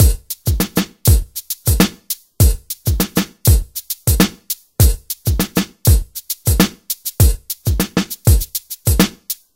hiphop drumloops soundbank 5
Free MP3 hiphop drumloops soundbank 5